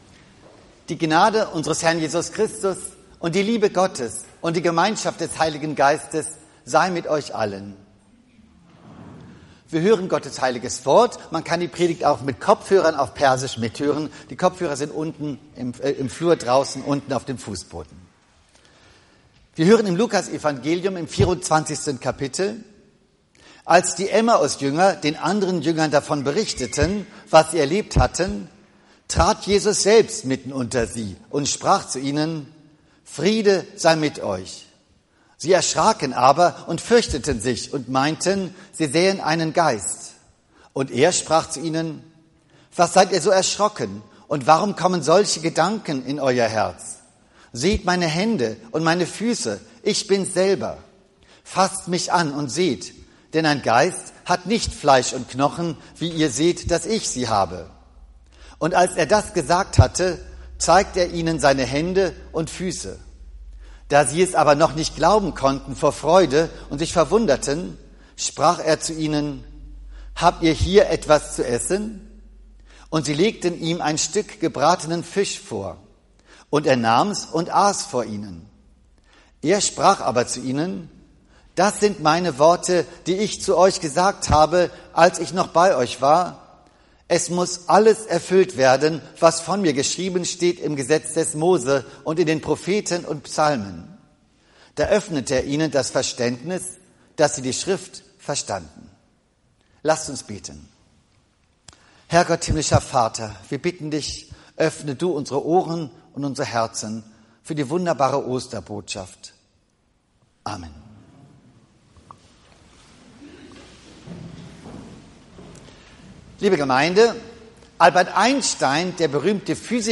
Die aktuelle Hörpredigt
Zeitnahe Predigt aus unserer Zionskirche 10.08.2025 8.